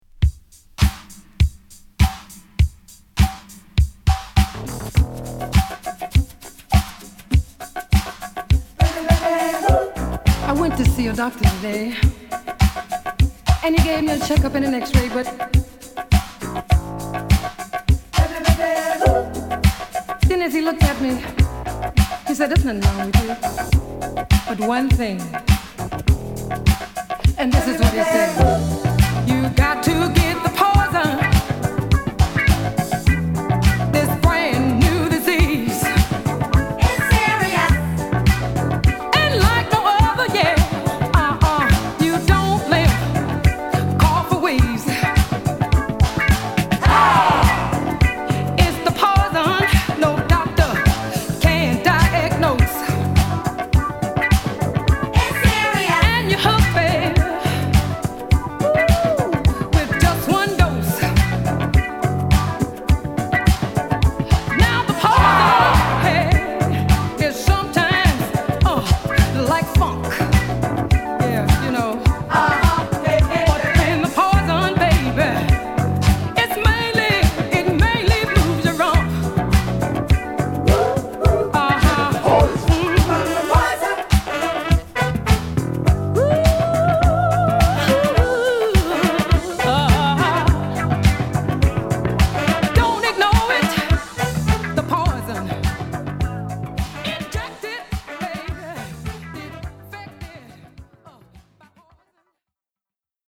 NYスタイルにアイランドディスコを掛けあわせた様なナイスなブギーチューンを披露。